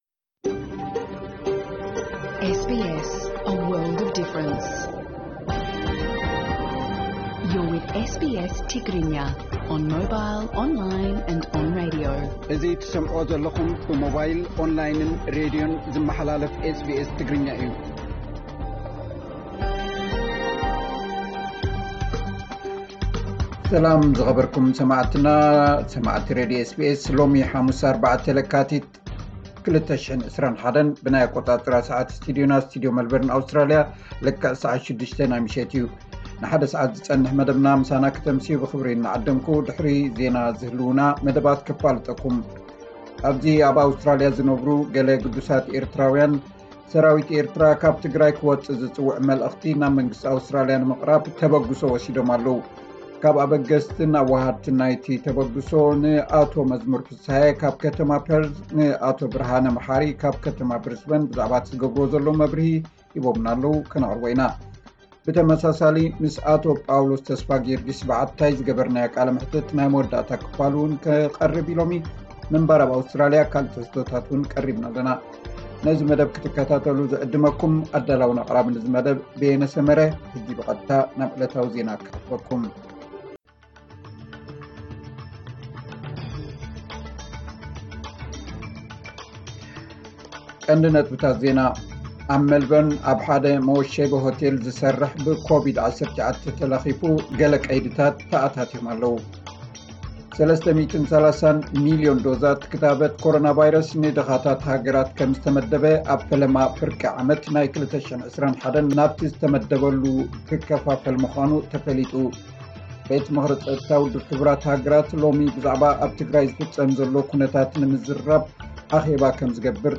ዕለታዊ ዜና SBS ትግርኛ 04 ለካቲት 2021